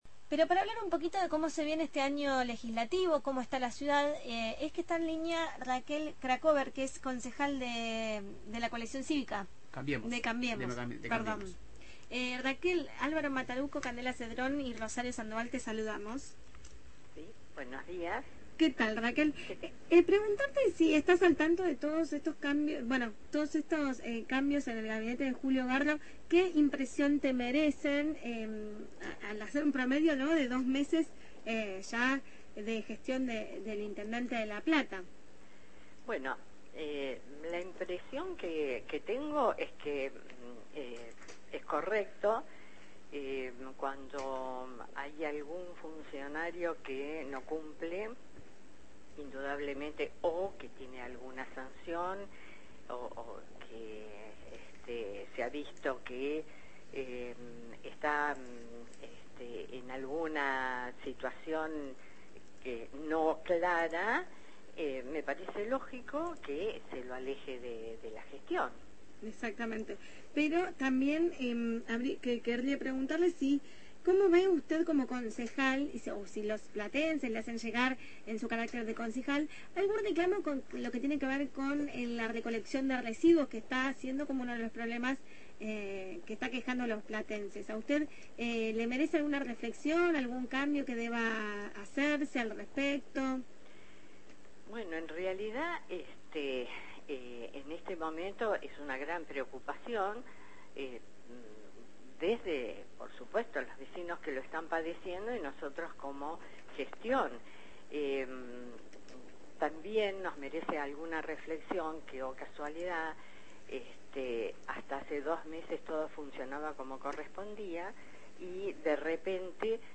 Raquel Krakover, concejal de La Plata por CC – Cambiemos